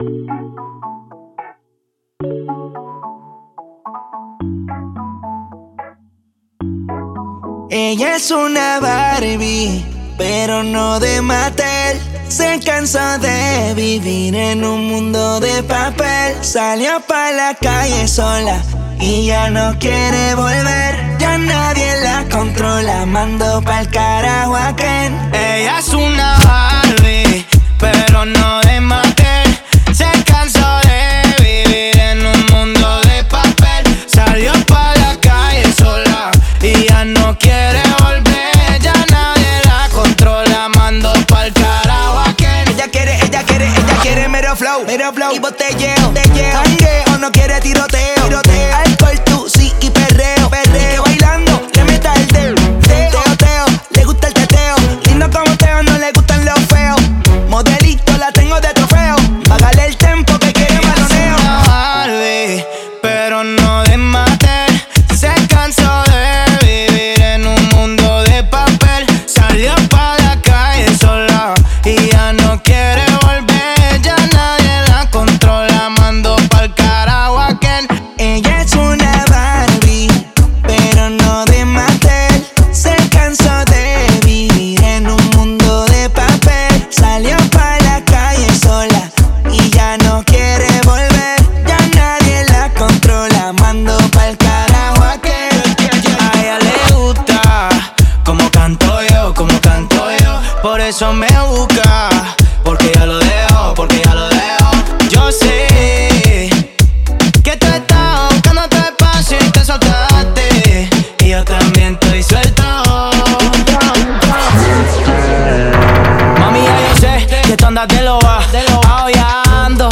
música urbana moderna
perreo.